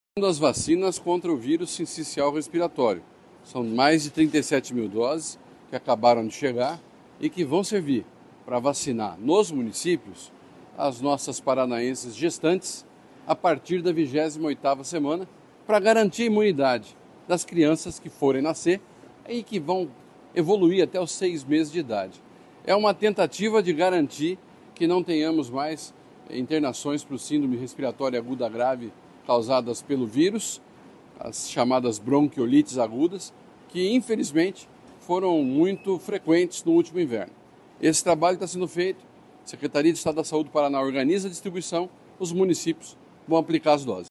Sonora do secretário da Saúde, Beto Preto, sobre o recebimento das primeiras vacinas contra o vírus sincicial respiratório para gestantes